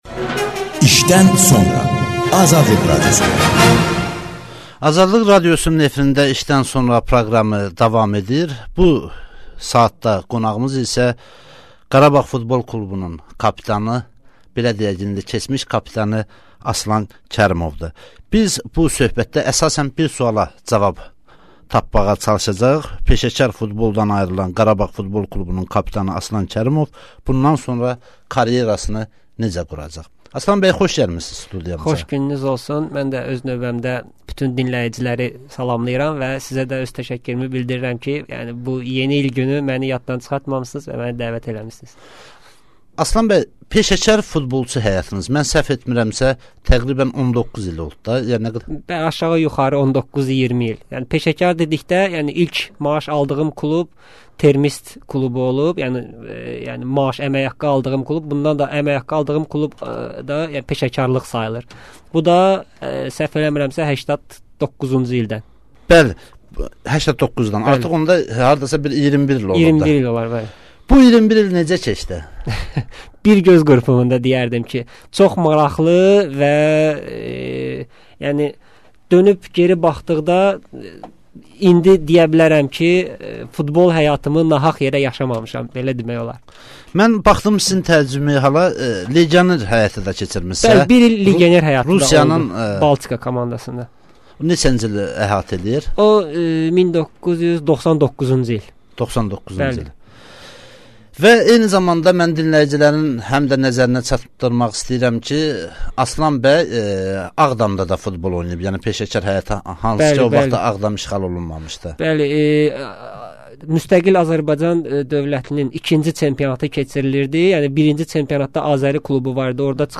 İşdən sonra - futbolçu Aslan Kərimovla söhbət